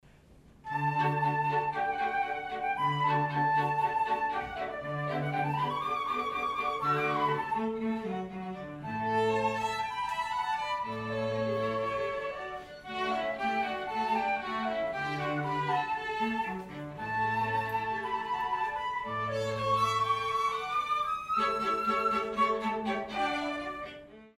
きのうは、加茂市民センターというところでの、コンサートでした。
ステージはなく、すぐ目の前にお客さんが座っているので、ちょっと緊張しますね。
会場の天井が低いので、音が反響してそんなに力まないでも大きな音が出ているような気がしますから、演奏自体は楽でしたね。
せっかくなので、この間買ったばかりのレコーダーで生録をやってきました。
でも、聴いてみたらほぼ満足のいく音で録れてましたね。
せっかくですのでフルート四重奏曲の頭だけ、